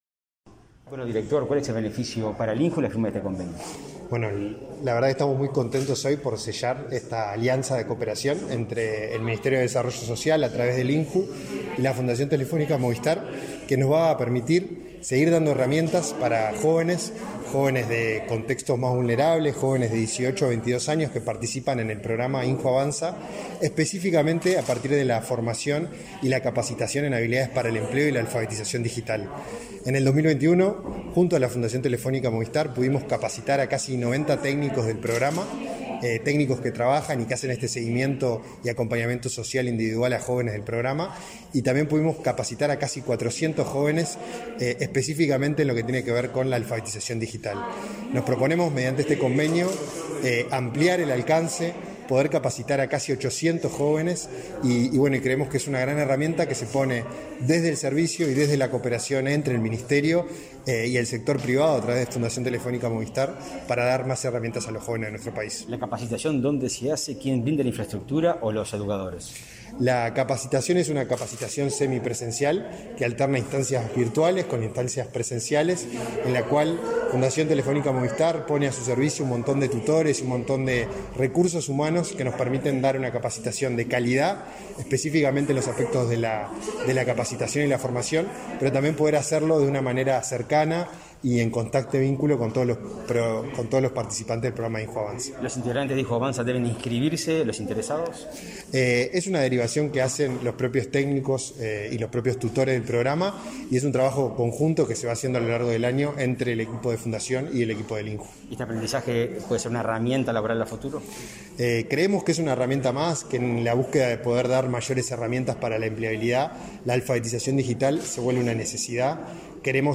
Declaraciones del director del Instituto Nacional de la Juventud, Felipe Paullier
Tras participar en la firma de convenio entre el Ministerio de Desarrollo Social y la Fundación Telefónica, este 21 de marzo, para beneficiar a 800